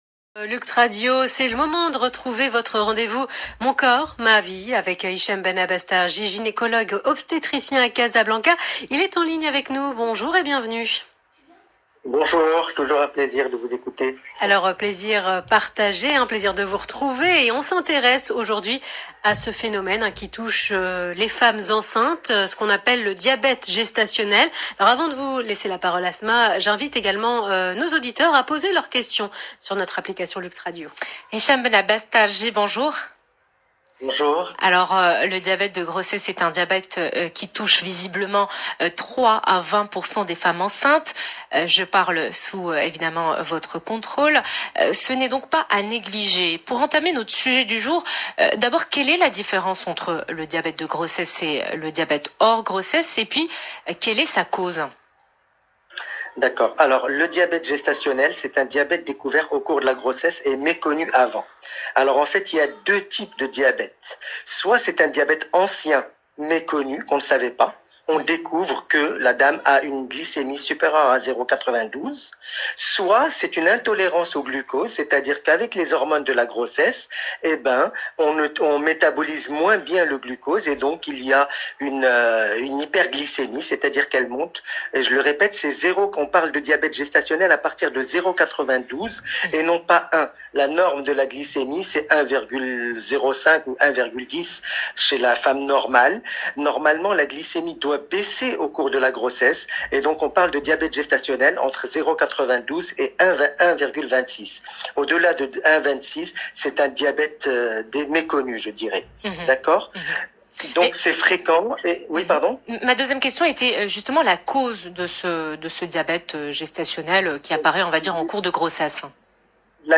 Essai de réponse au cours de cette interview dans l’Heure essentielle sur LUXE RADIO